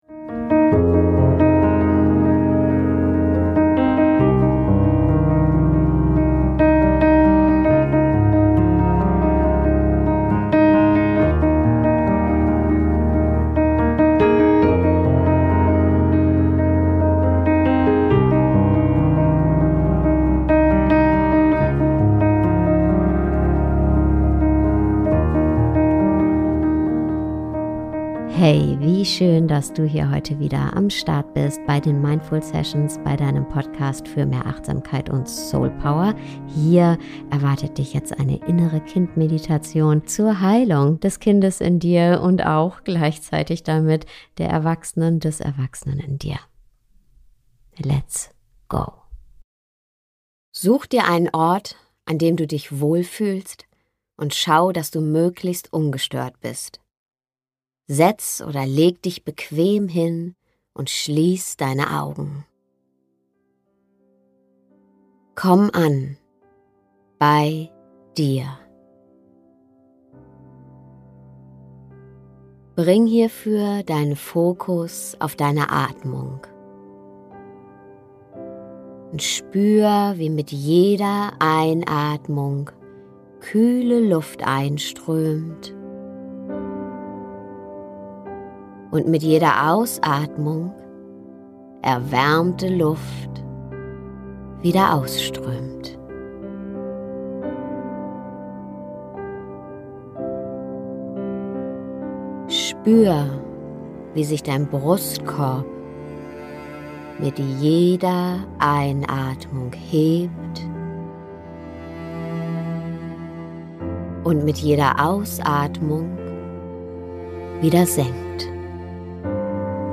Heile dein inneres Kind - Meditation
Diese Meditation führt dich behutsam zu deinem inneren Kind und eröffnet einen heilsamen Dialog.